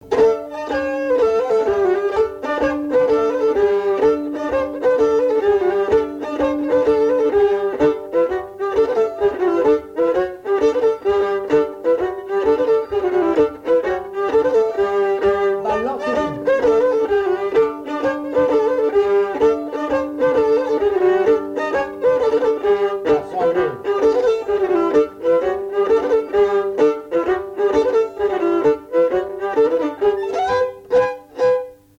Couplets à danser
danse : branle : avant-deux
Pièce musicale inédite